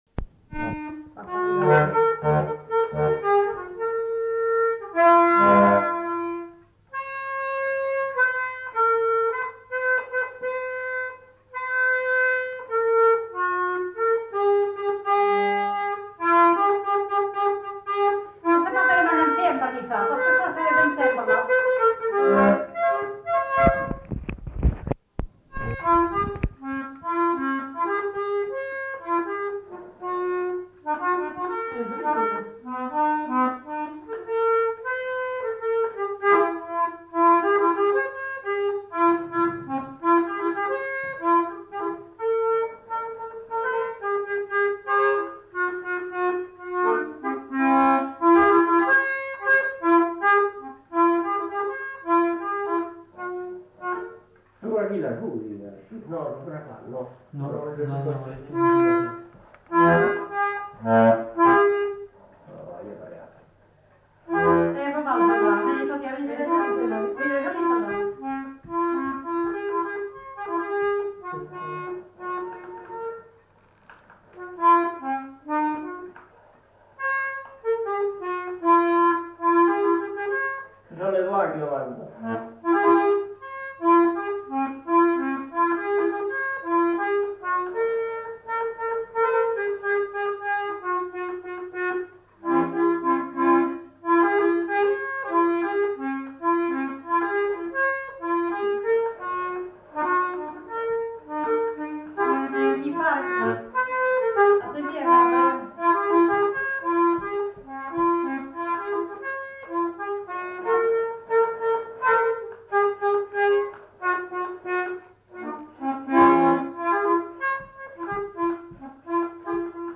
Polka
Aire culturelle : Néracais
Lieu : Sainte-Maure-de-Peyriac
Genre : morceau instrumental
Instrument de musique : accordéon diatonique
Danse : polka
Notes consultables : Le musicien fredonne l'air en fin de séquence.